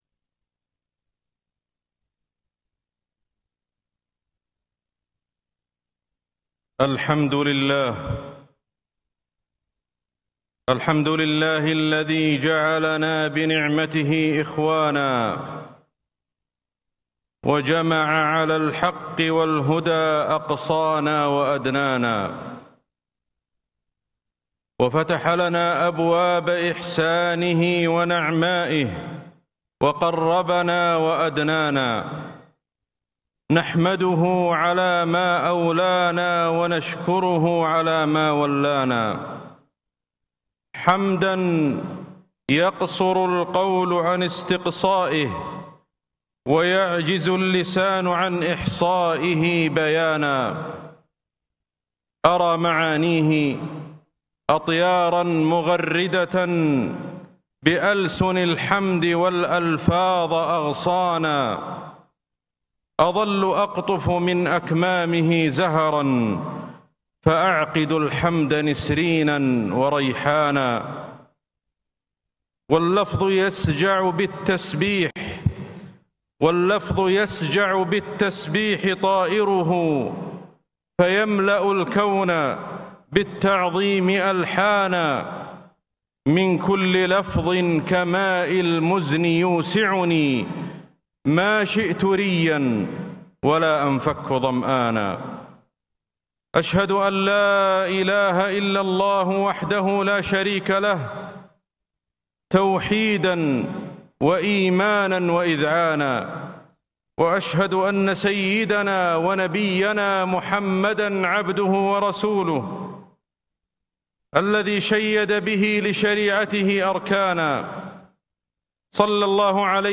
خطبة الجمعة ٨ ربيع الآخر ١٤٤٦هـ بمسجد الإستقلال الكبير بإندونيسيا 🇮🇩